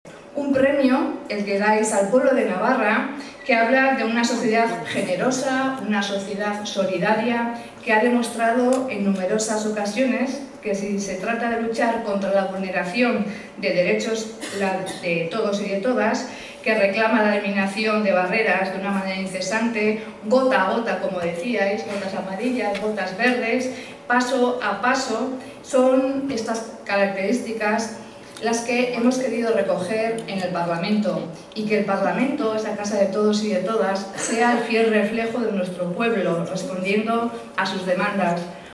Por su parte, Ainhoa Aznárez, que además de presidir la gala asistió también en calidad de premiada (como presidenta del Parlamento autonómico), agradeció a nuestra Organización la convocatoria de unos galardones en los que -como es el caso del Parlamento Navarro- se premia en realidad a “una sociedad generosa y solidaria